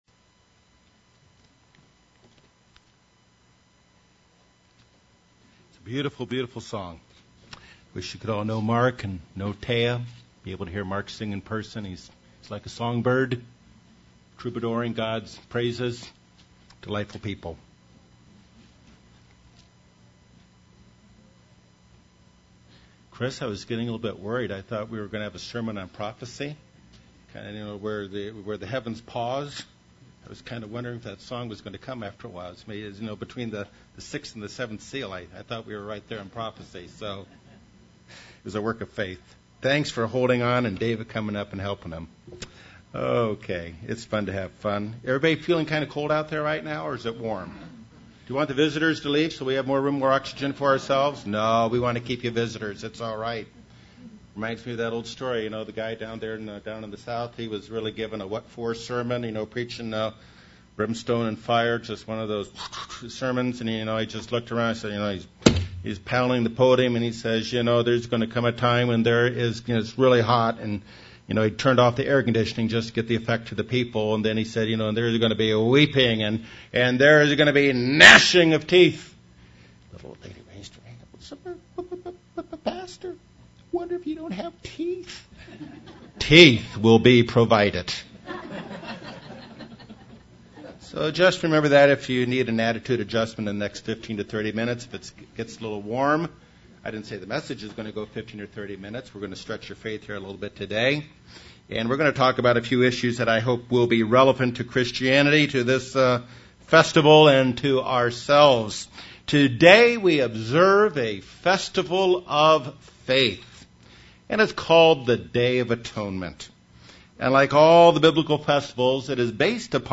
There is nothing that will keep us apart from God. The veil of the Temple will be removed and all mankind will have the opportunity to have Atonement with God. This is a Holy Day message from the Day of Atonement.